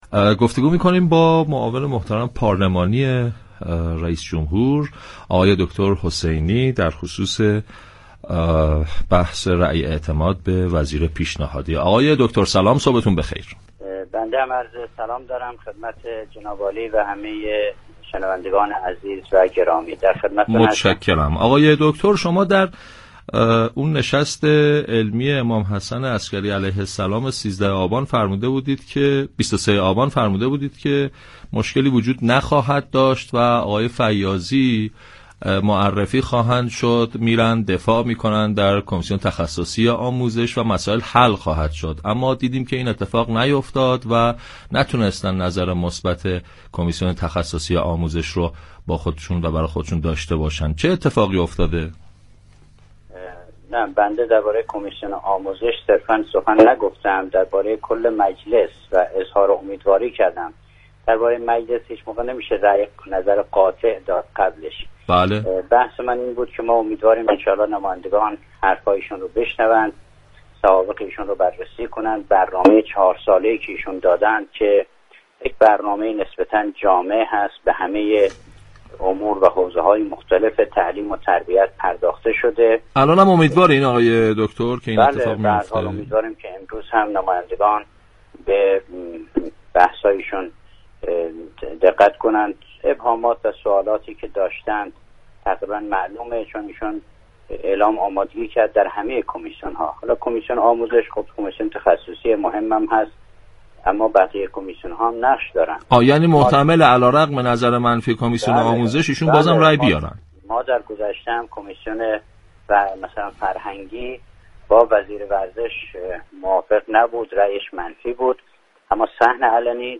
به گزارش پایگاه اطلاع رسانی رادیو تهران، سید محمد حسینی معاون پارلمانی رئیس‌جمهوری در گفتگو با پارك شهر رادیو تهران در خصوص رأی اعتماد نمایندگان مجلس به آقای فیاضی گزینه پیشنهادی دولت برای وزارتخانه آموزش‌وپرورش ، گفت: نمایندگان مجلس امروز صحبت‌های آقای فیاضی را خواهند شنید و امیدواریم ایشان در صحن علی مجلس رأی بیاورند.